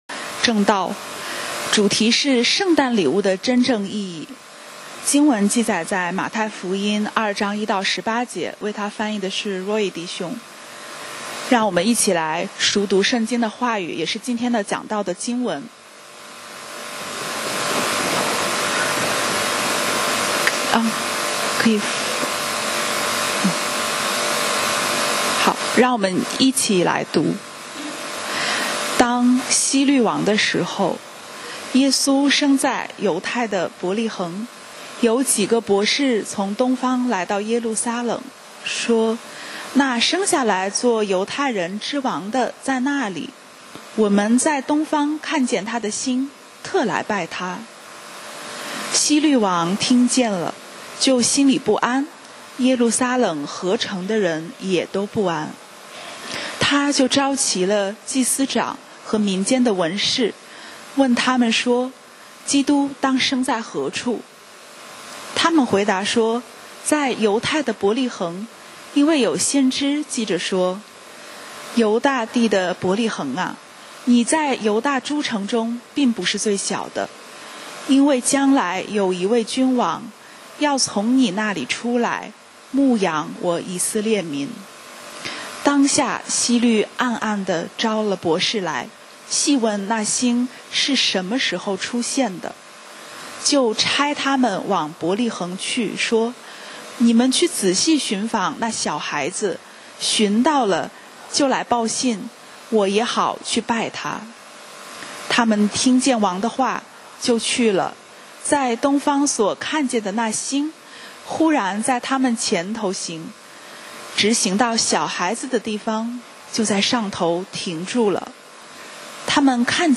講道 Sermon 題目 Topic： 圣诞礼物的真正意义 經文 Verses：太2：1－18. 1當希律王的時候、耶穌生在猶太的伯利恆．有幾個博士從東方來到耶路撒冷、說、2那生下來作猶太人之王的在那裡。